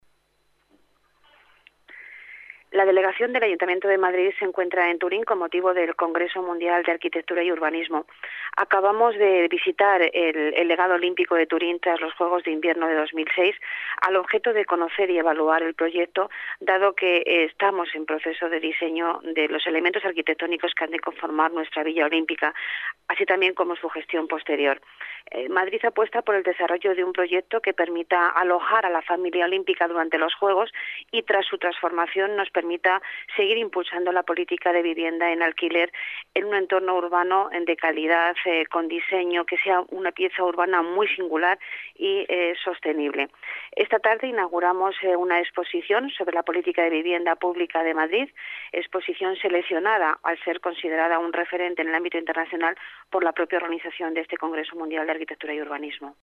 Nueva ventana:Declaraciones delegada Urbanismo, Pilar Martínez: Madrid muestra vivienda pública en Turín